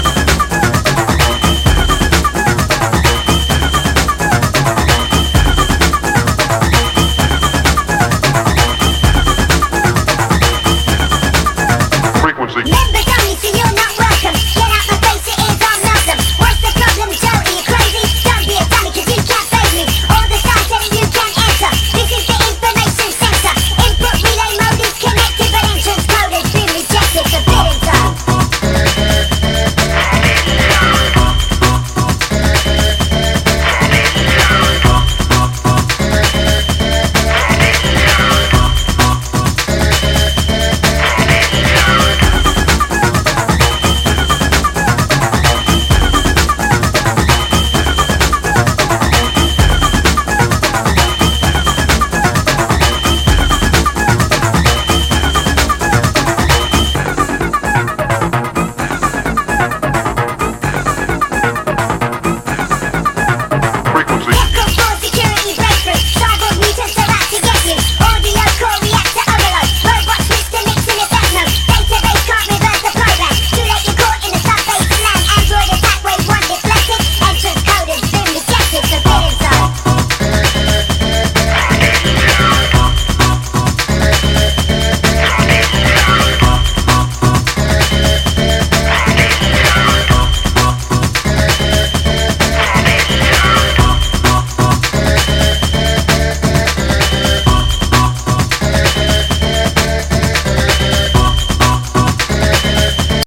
Old Skool Hardcore / Old Skool Techno / Old Skool Breakbeat